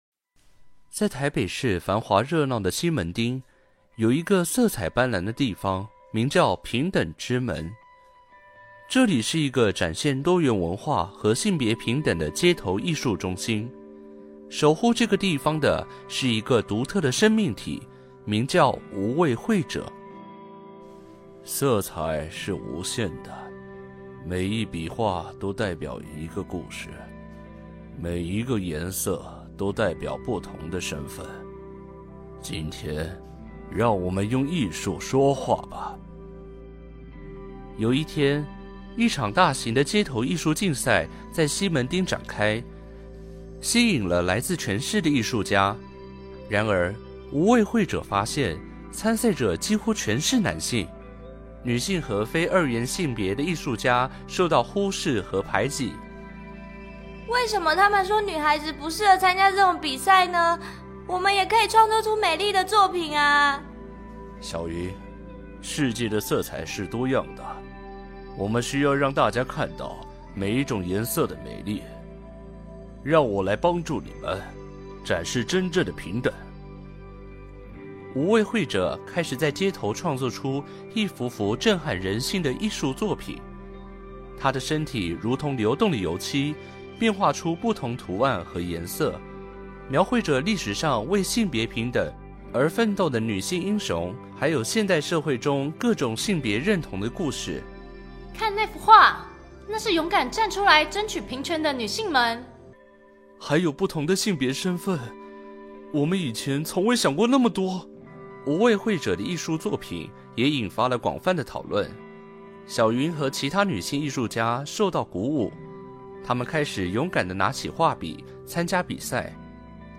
故事有聲書